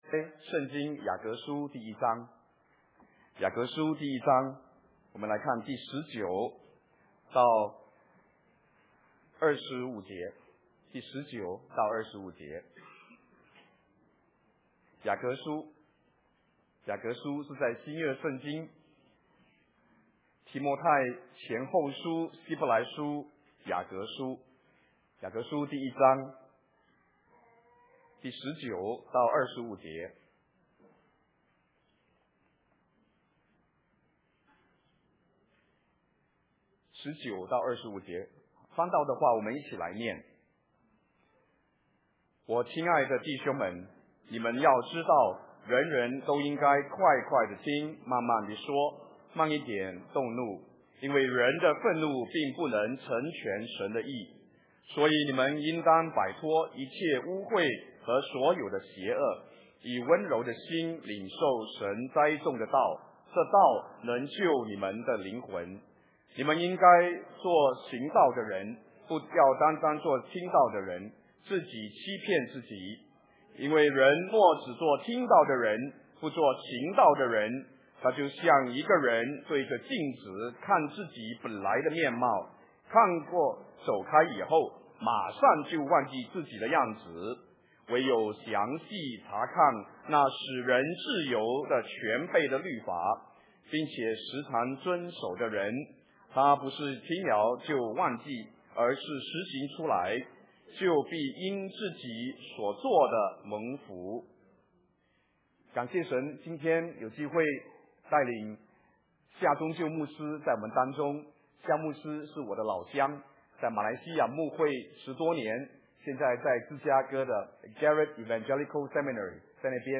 Mandarin Sermons – Page 89 – 安城华人基督教会
Mandarin Sermons Home / Mandarin Sermons